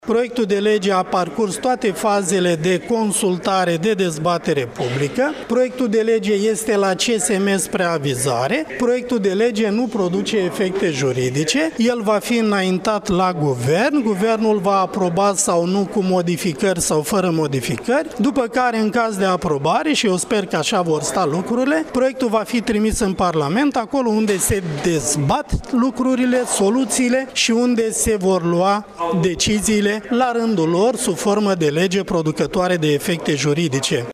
Modificarea legilor Justiţiei se face respectând procedurile legale, a dat asigurări astăzi, la Iaşi, ministrul de resort Tudorel Toader.